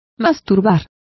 Complete with pronunciation of the translation of masturbated.